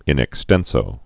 (ĭn ĕk-stĕnsō)